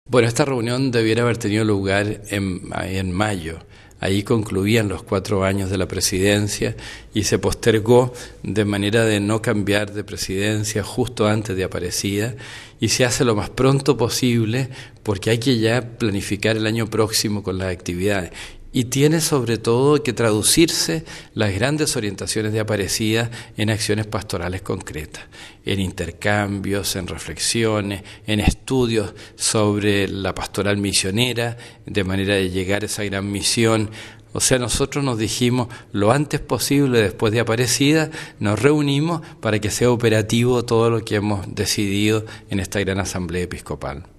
Uno de ellos, el Cardenal Francisco Javier Errázuriz Ossa, arzobispo de Santiago de Chile y presidente del CELAM ha concedido una entrevista a nuestra emisora.